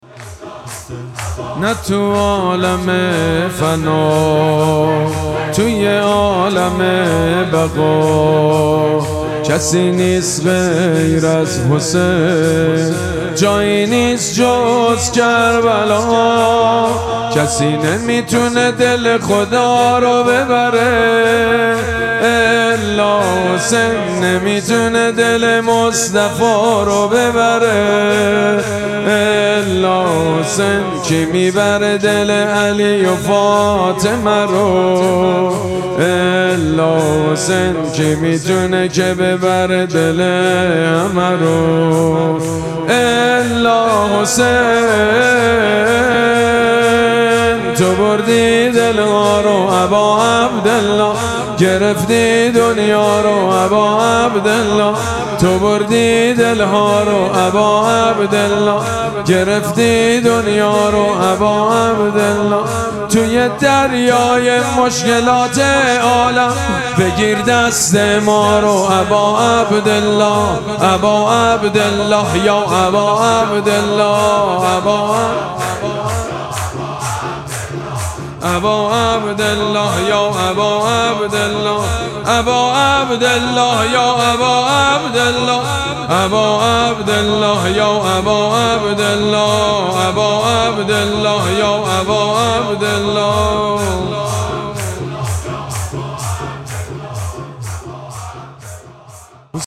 مراسم مناجات شب پنجم ماه مبارک رمضان
شور
مداح
حاج سید مجید بنی فاطمه